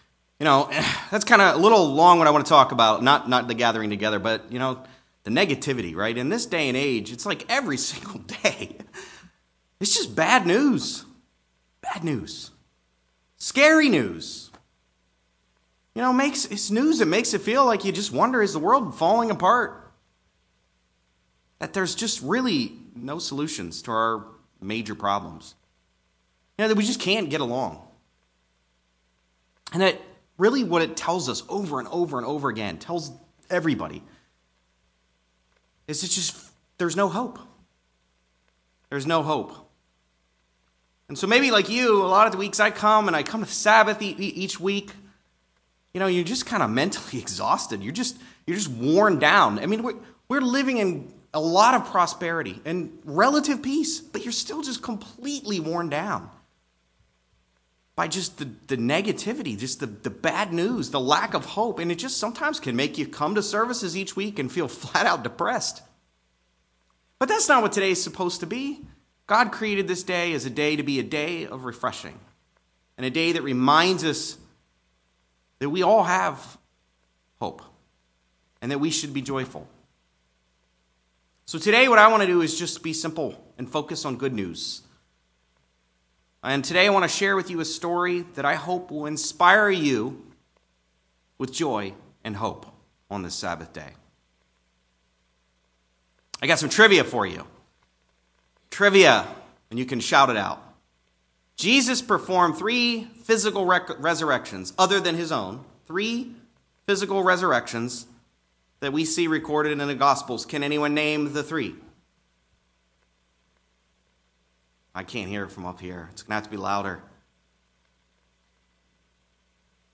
Sermons
Given in Greensboro, NC Raleigh, NC